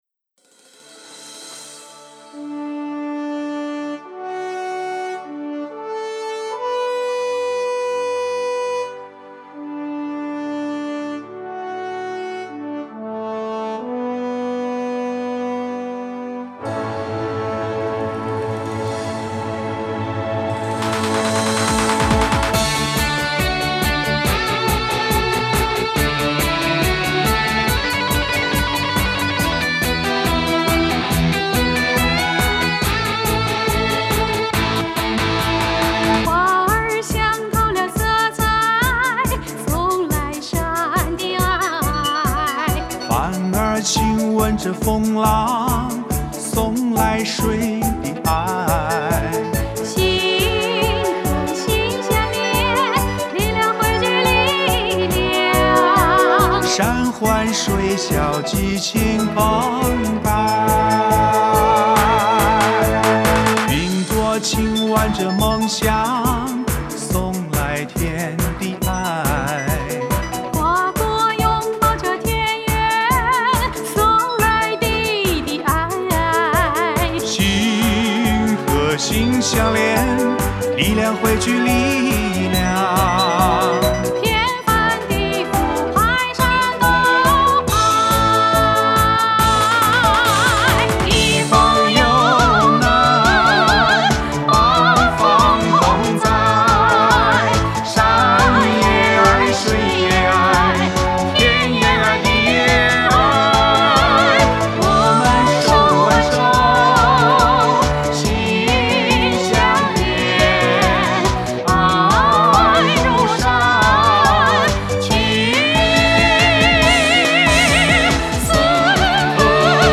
原创歌曲